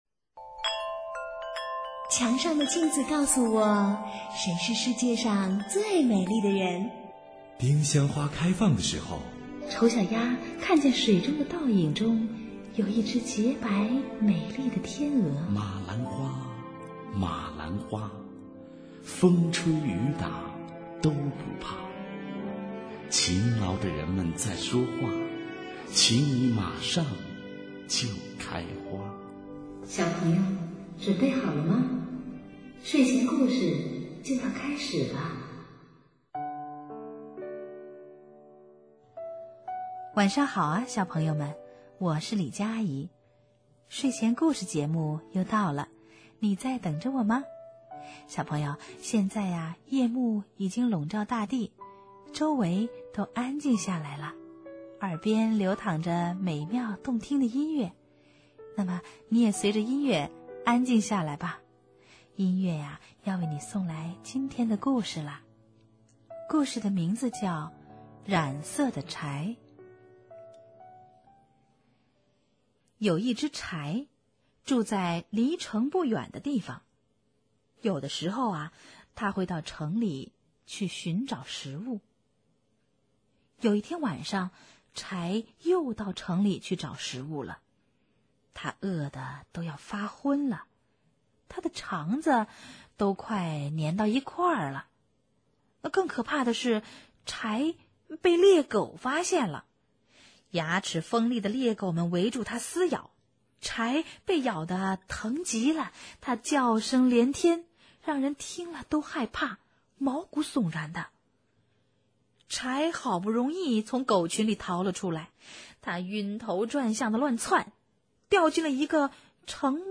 经典故事,听故事,mp3音频故事会